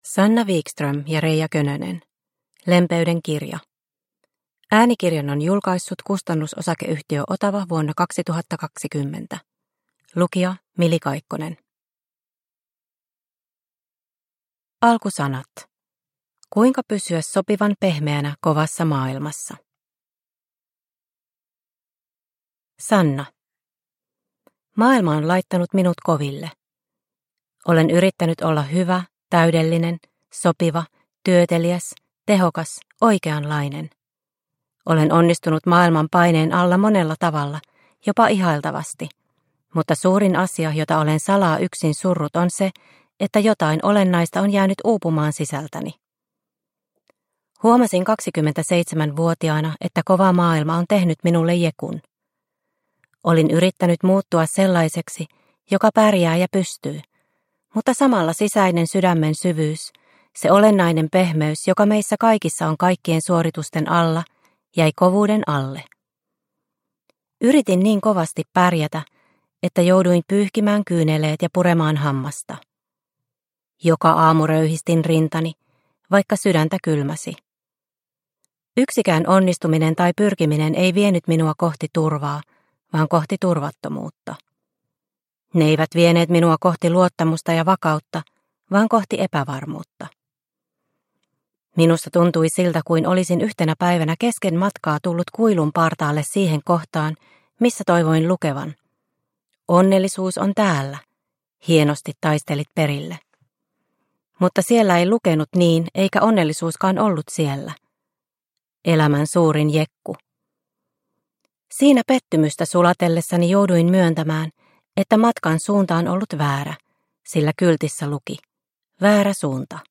Lempeyden kirja – Ljudbok – Laddas ner